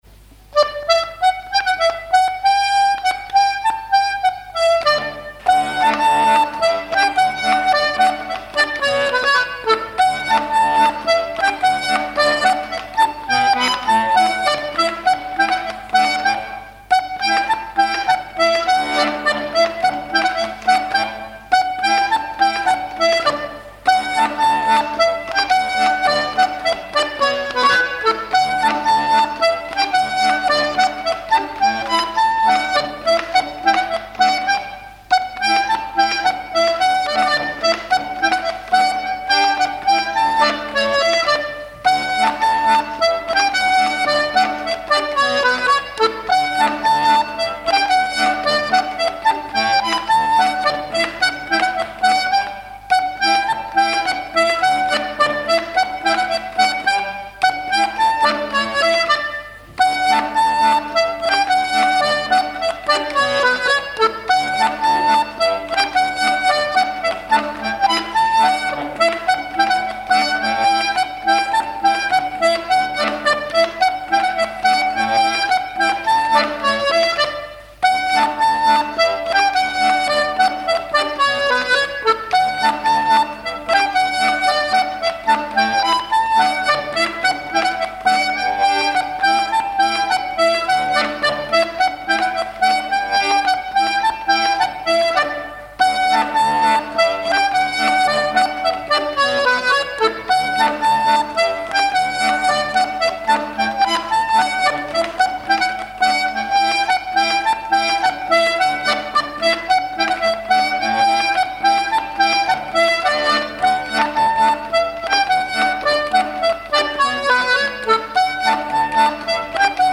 danse : pas d'été
airs de danse à l'accordéon diatonique
Pièce musicale inédite